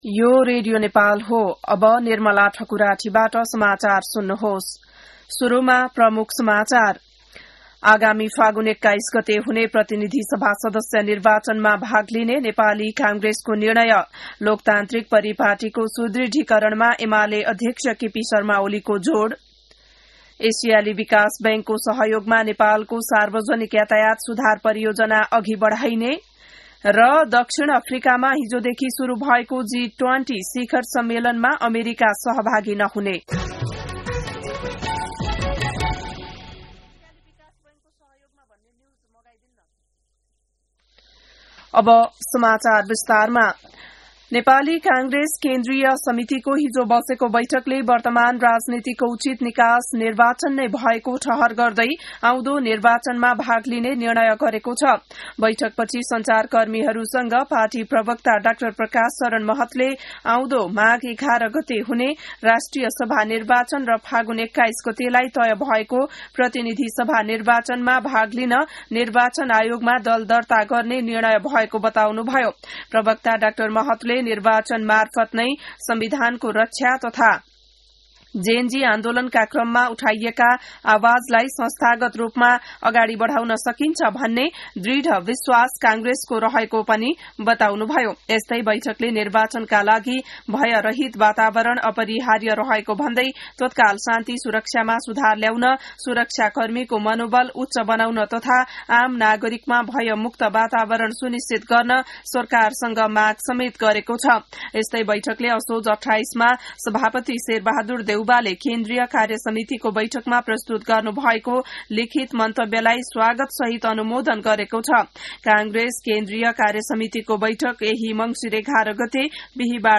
An online outlet of Nepal's national radio broadcaster
बिहान ९ बजेको नेपाली समाचार : ७ मंसिर , २०८२